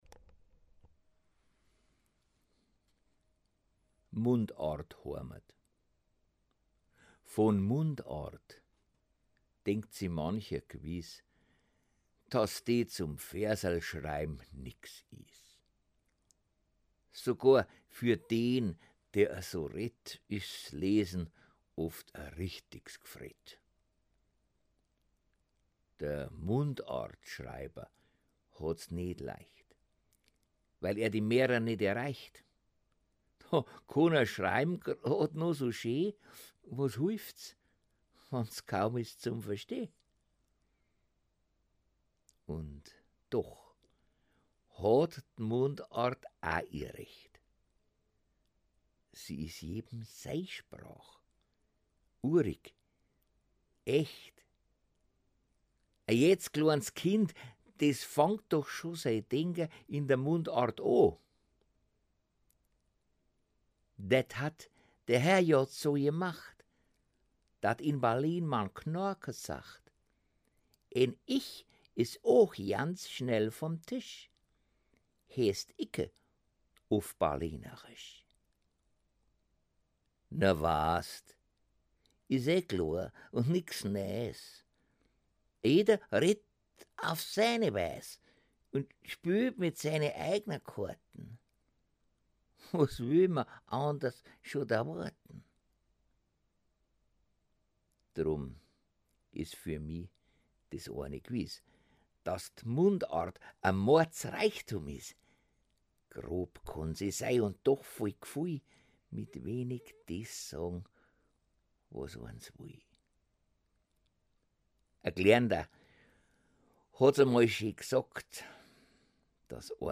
ste-085_mundart-hoamat.mp3